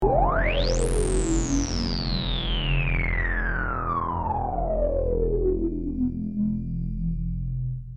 Free MP3 vintage Sequential circuits Pro-600 loops & sound effects 2
Sequencial Circuits - Prophet 600 42